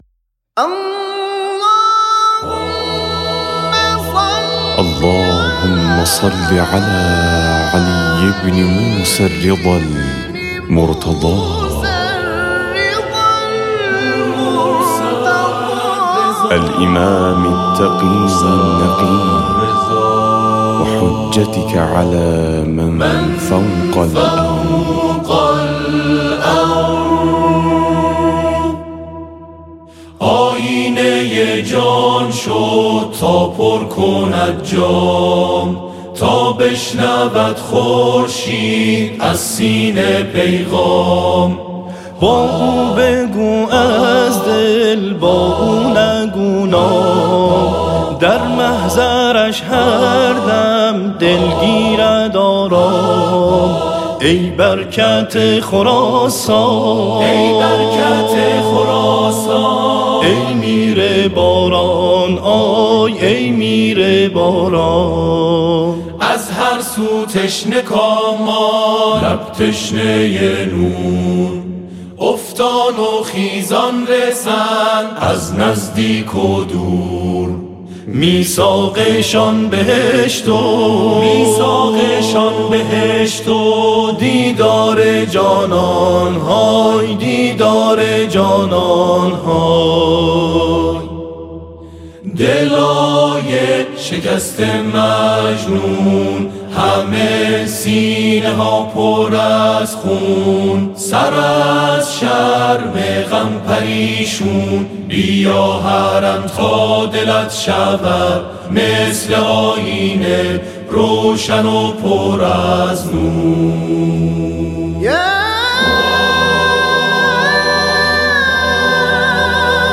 براساس فرم موسیقی آکاپلا